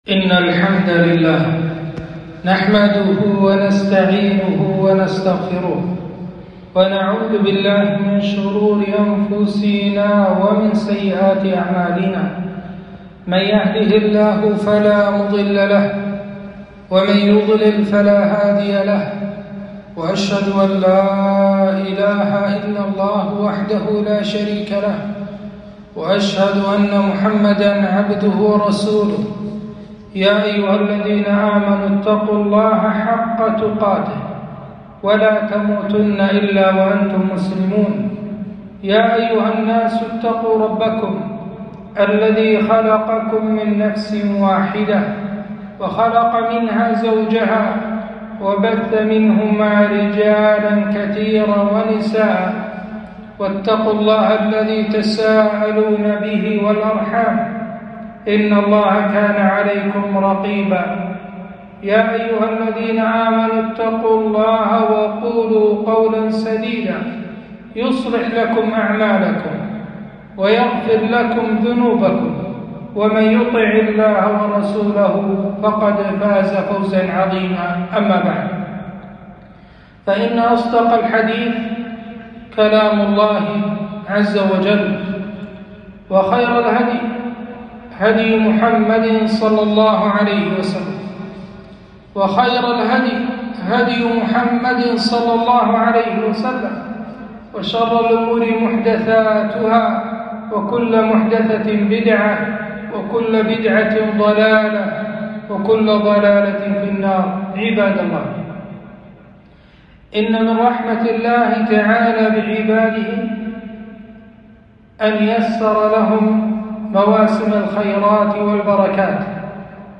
خطبة - فضائل عشر ذي الحجة